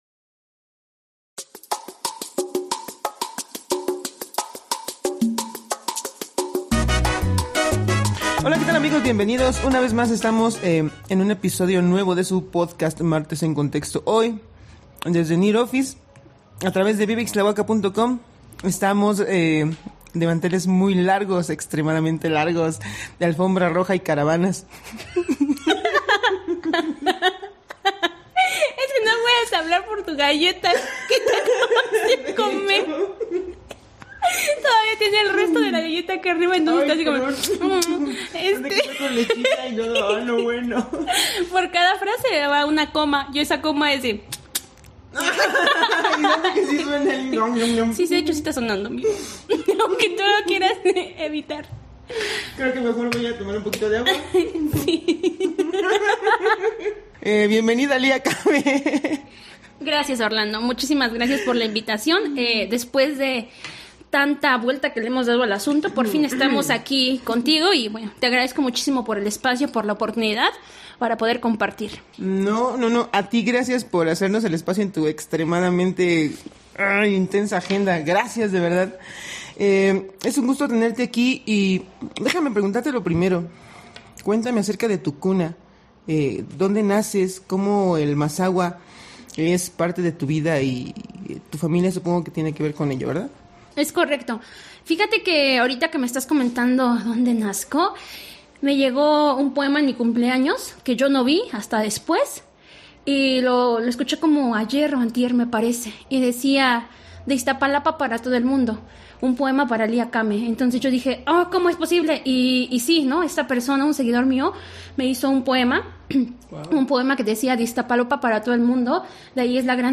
Locación: NEAR OFFICE.